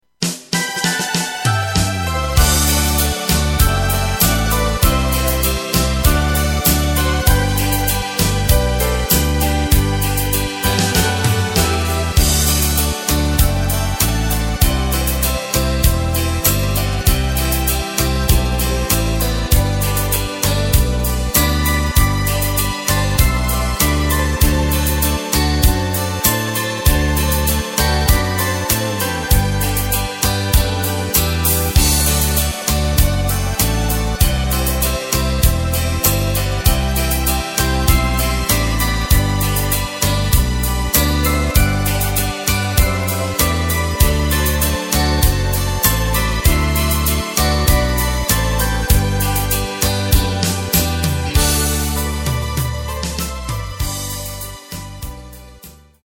Takt:          4/4
Tempo:         98.00
Tonart:            G
Schlager aus dem Jahr 2002!
Playback mp3 mit Lyrics